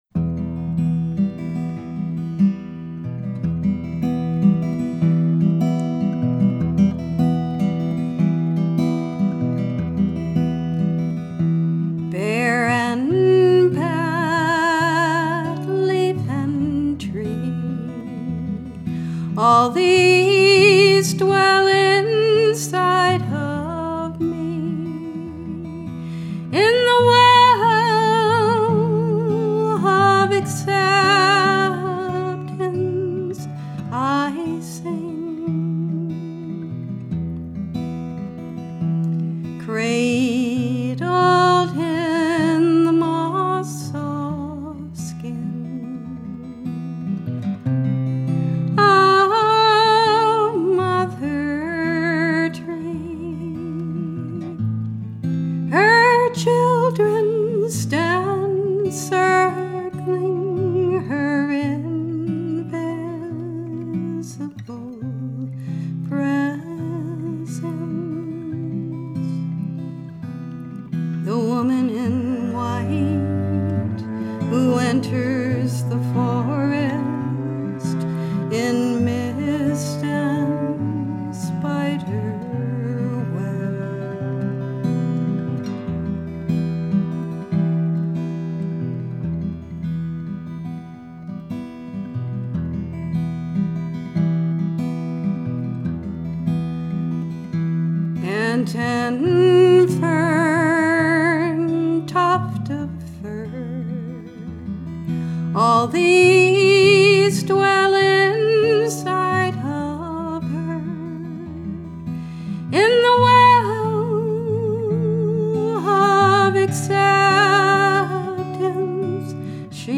cello
vocals, guitar, oboe, English horn